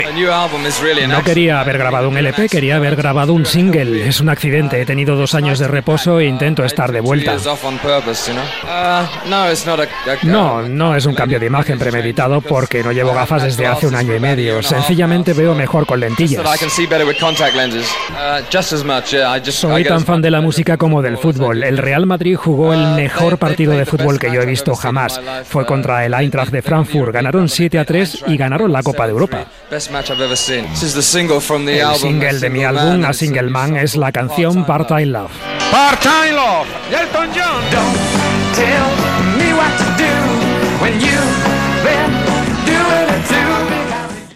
Respostes d'Elton John a les preguntes, en una roda de premsa feta a Madrid, amb traducció al castellà.